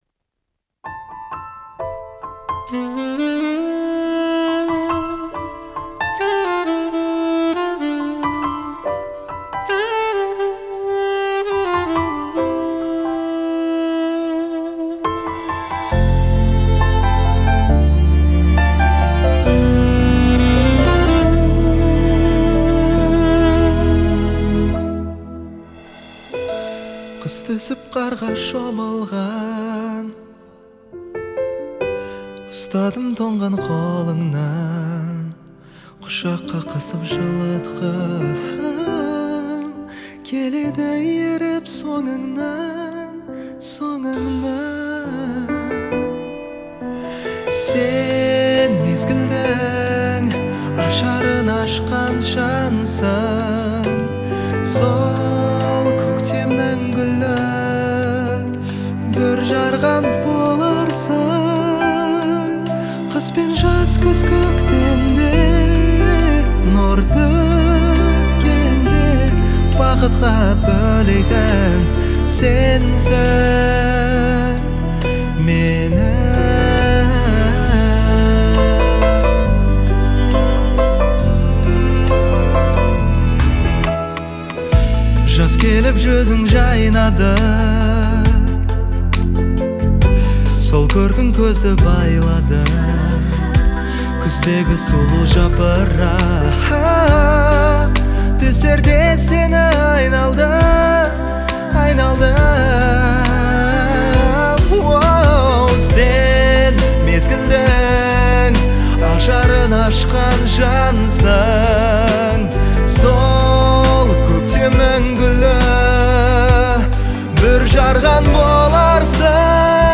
сочетая традиционные мелодии с современными аранжировками.